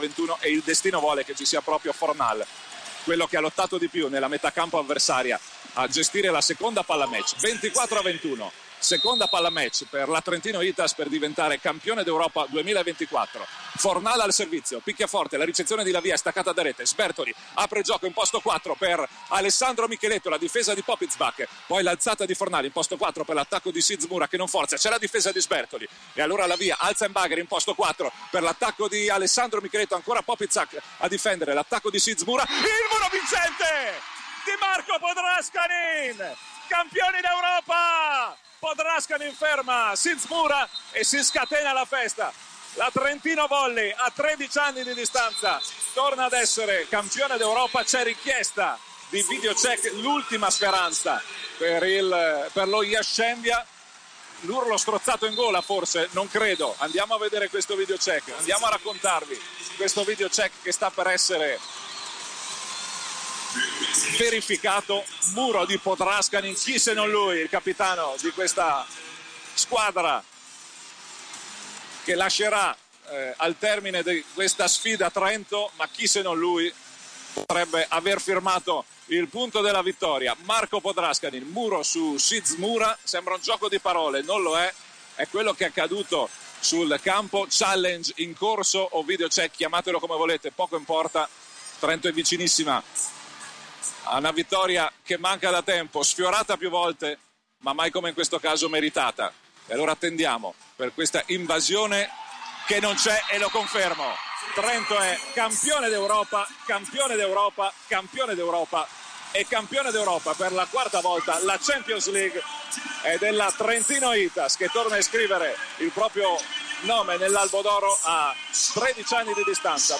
Radiocronache ultimi punti